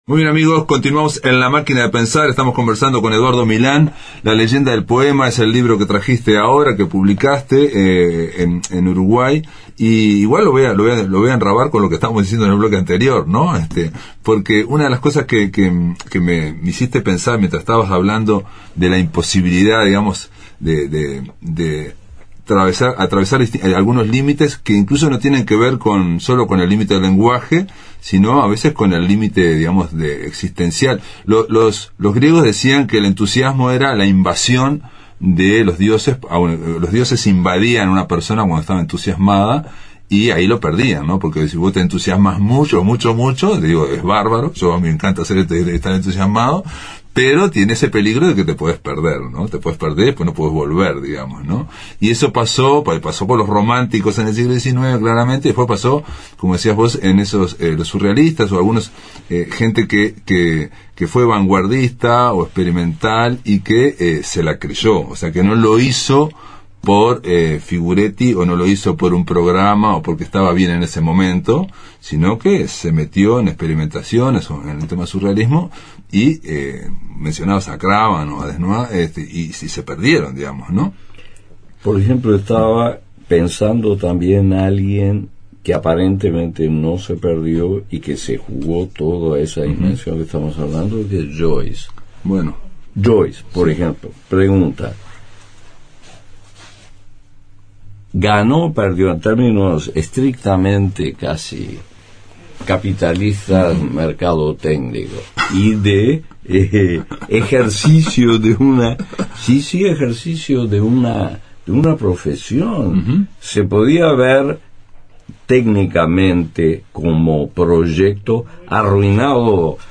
Entrevista a Eduardo Milán